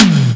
prcTTE44009tom.wav